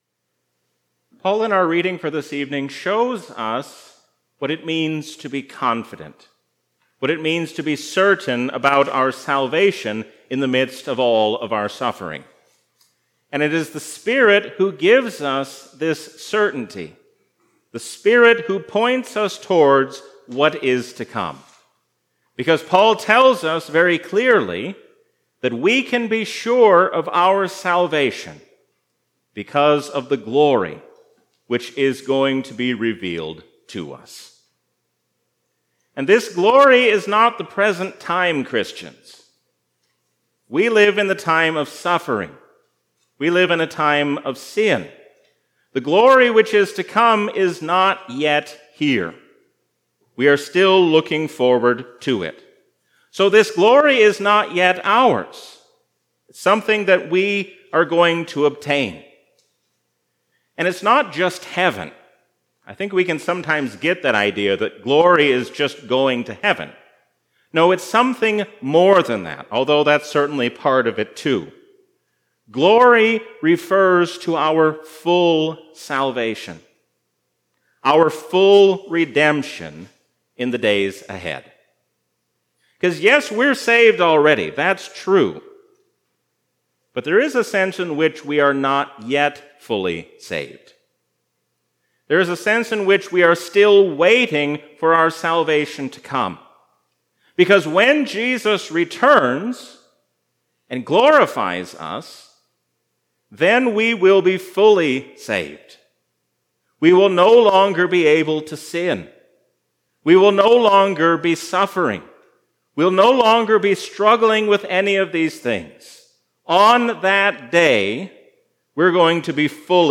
A sermon from the season "Trinity 2021." Don't look to the world and its empty dreams of utopia, but look to the Lord who will make all things new.